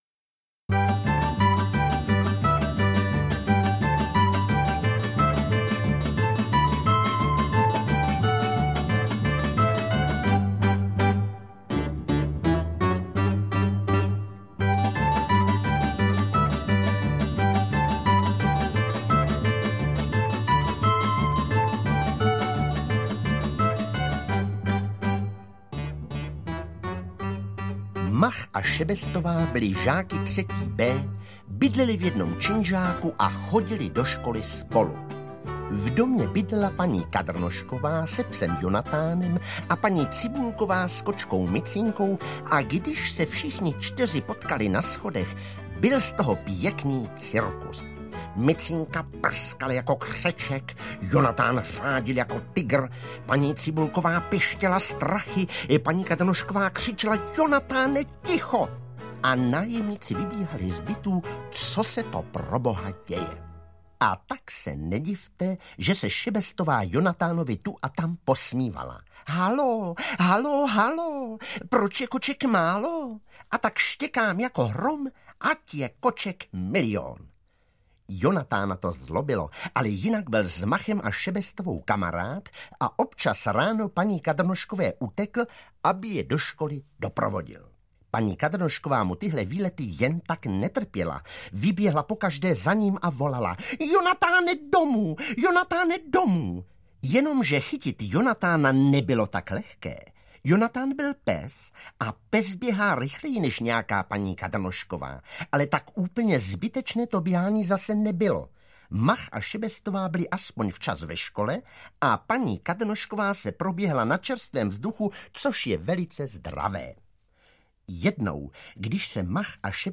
Ukázka z knihy
• InterpretPetr Nárožný